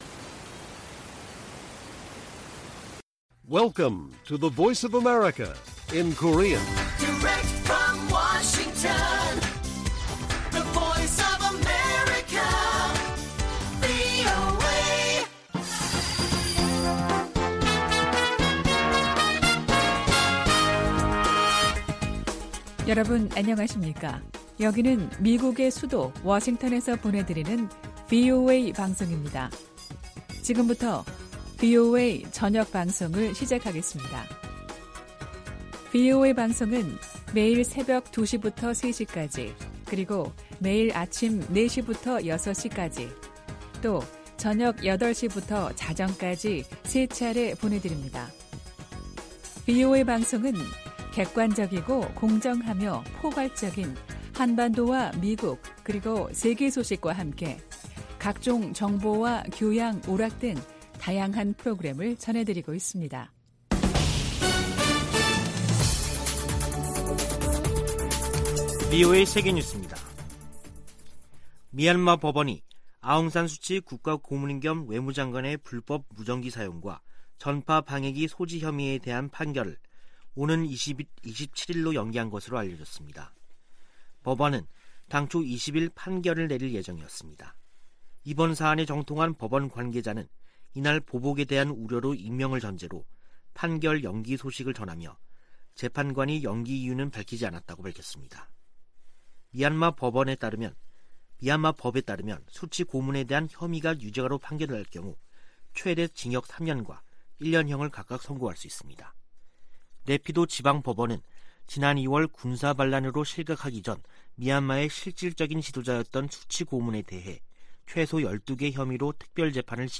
VOA 한국어 간판 뉴스 프로그램 '뉴스 투데이', 2021년 12월 21일 1부 방송입니다. 북한은 미사일 개발 등 대규모 군사비 지출로 만성적인 경제난을 겪고 있다고 미 CIA가 분석했습니다. 미국의 전문가들은 북한이 ICBM 대기권 재진입과 핵탄두 소형화 등 핵심 기술을 보유했는지에 엇갈린 견해를 내놓고 있습니다. '오미크론' 변이 출현으로 북-중 교역 재개가 지연됨에 따라 북한 주민들이 겨울나기에 한층 어려움을 겪고 있습니다.